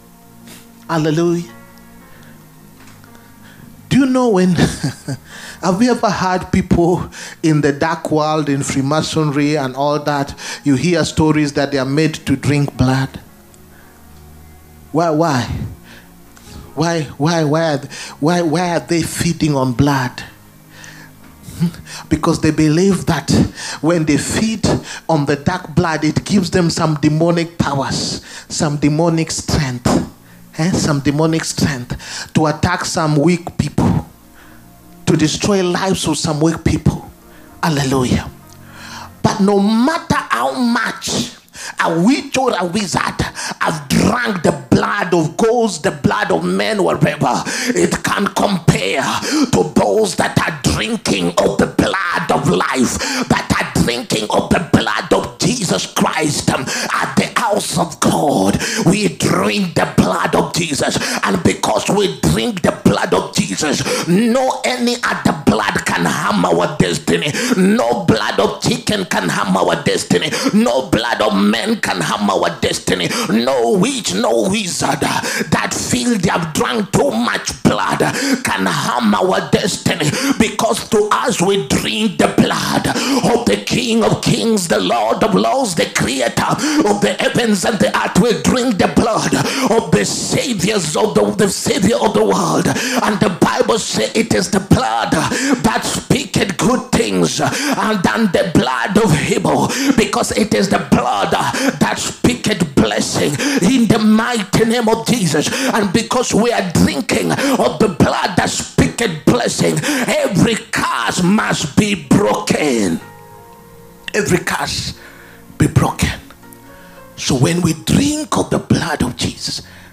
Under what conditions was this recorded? SUNDAY BLESSINGS SERVICE. WHY WE NEED BLESSINGS. 27TH APRIL 2025.